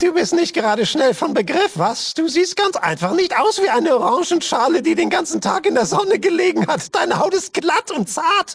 Fallout 2: Audiodialoge